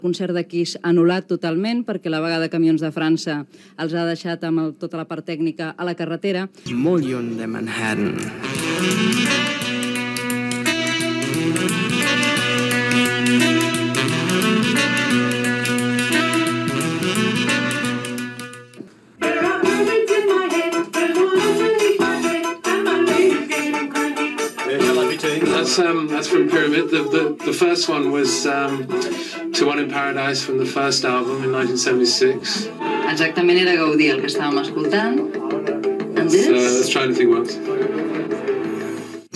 Anulat el concert de Kiss a Barcelona, indicatiu del programa, inici de l'entrevista al músic Alan Parsons
FM